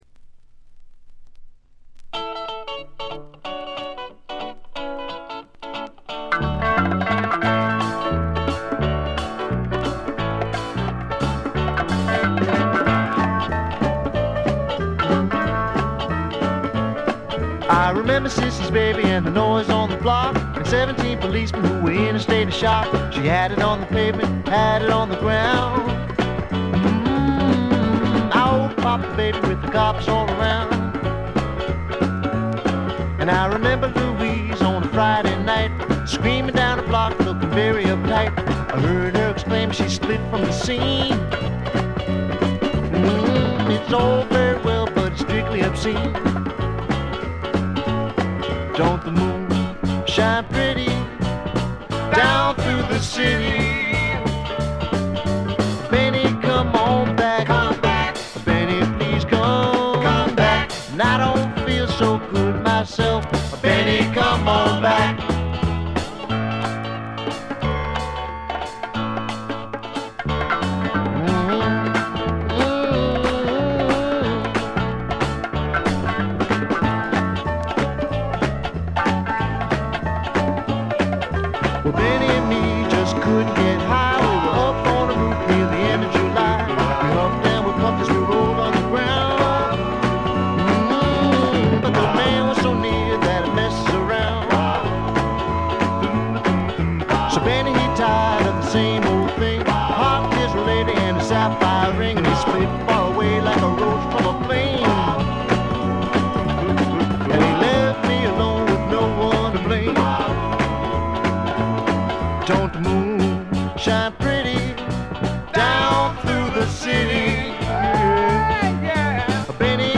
軽微なチリプチ程度。
試聴曲は現品からの取り込み音源です。
Recorded At - Sound Exchange Studios